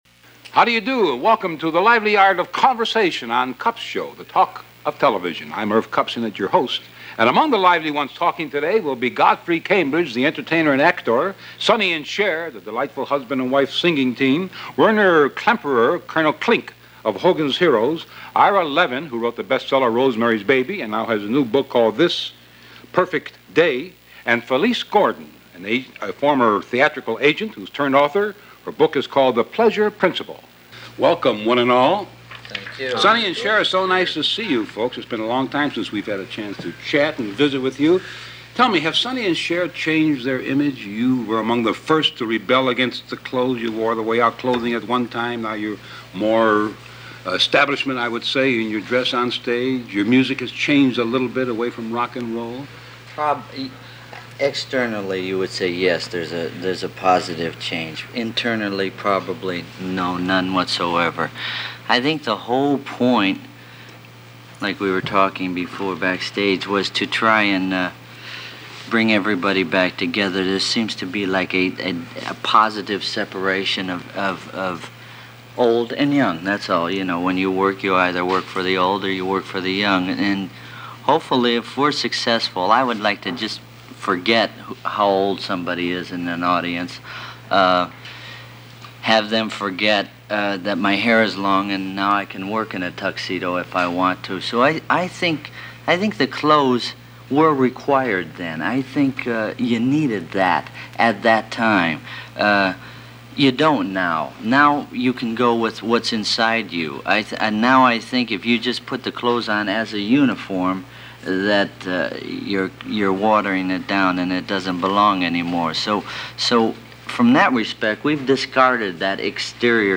The other thing – the date of this interview is May 20, 1970, a few weeks since the deadly demonstrations and shootings by National Guard troops at Kent and Jackson State Universities.